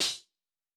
TC PERC 04.wav